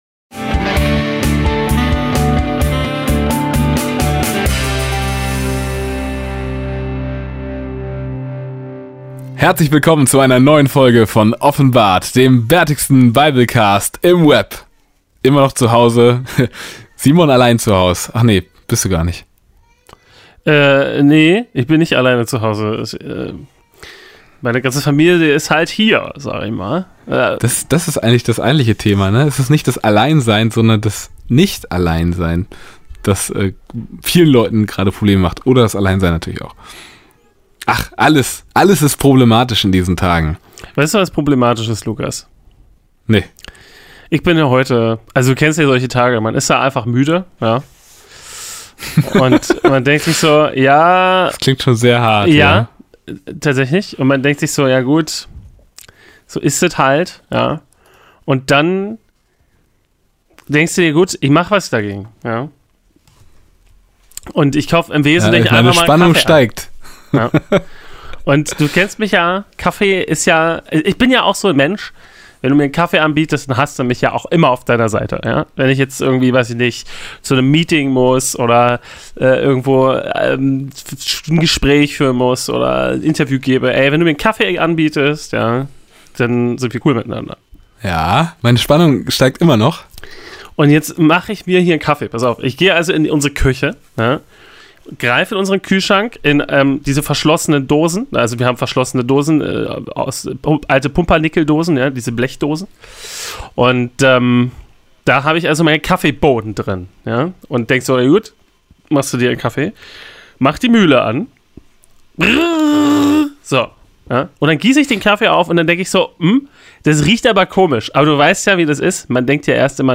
Vor der dramatischen Kulisse eines schreienenden Kindes verfolgen wir die Nachwahl des zwölften Apostels. Was das mit Johannes dem Täufer zu tun hat, warum Justus Pech hat und ob Basisdemokratie besser ist als Würfeln – das sind heute unsere Themen.